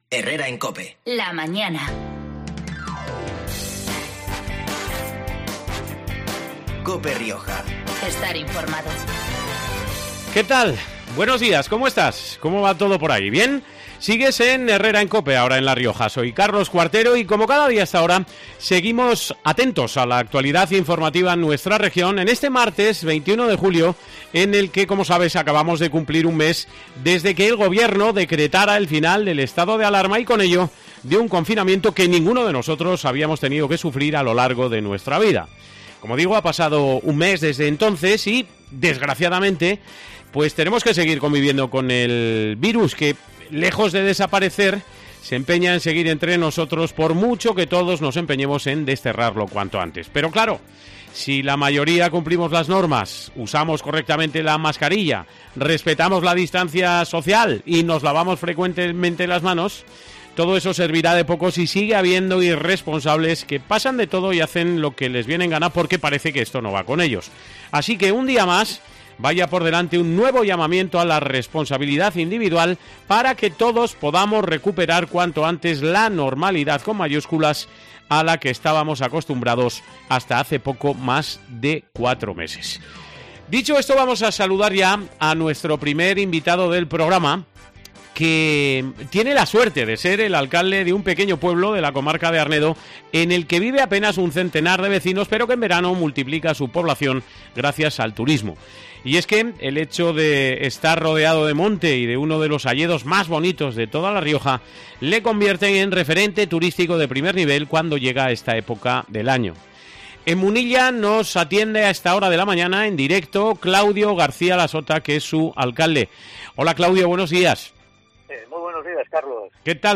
Su alcalde , Claudio García Lasota , ha pasado este mediodía por los micrófonos de COPE Rioja para describir las no pocas bondades de un pueblo que, en verano, incrementa notablemente su población gracias al turismo .